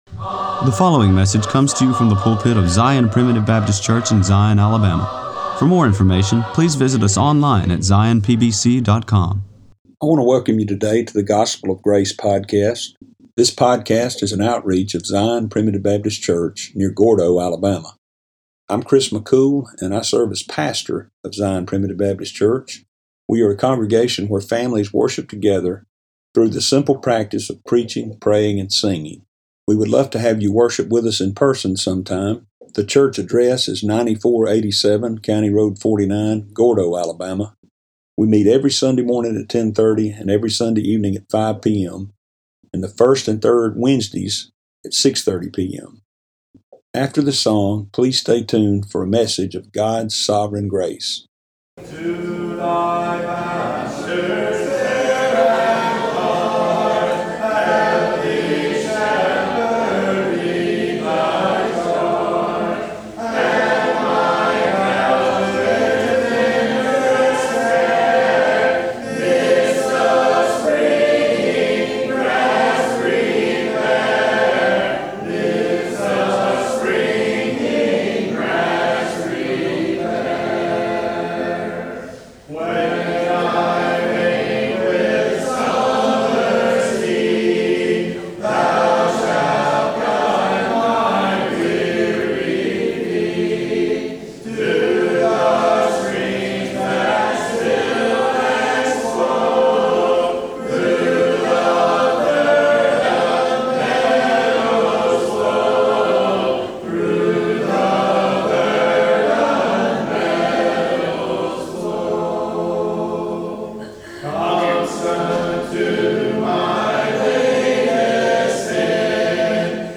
preached on September 6